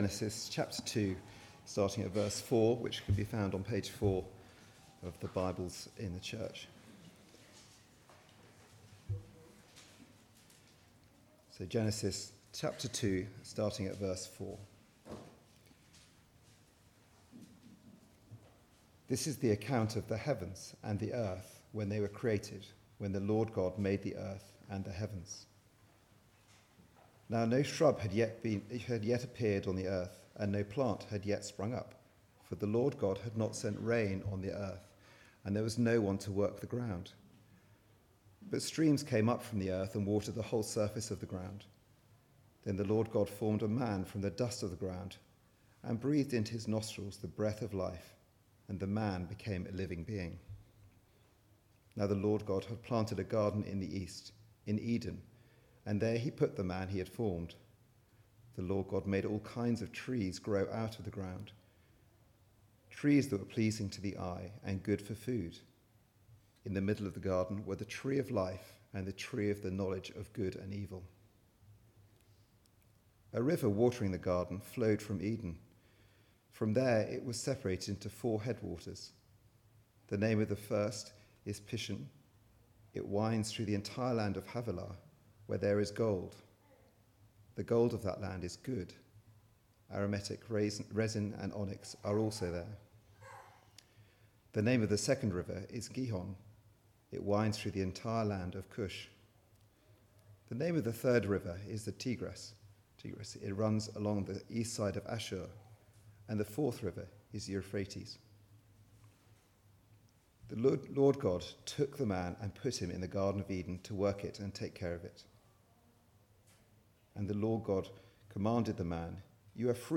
Passage: Genesis 2:4-25 Service Type: Weekly Service at 4pm